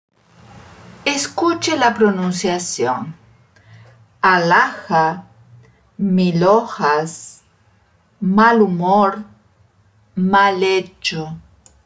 Nunca pronuncie com o som do português.
A letra “H” em espanhol não tem som.
Ouça aqui a pronúncia correta.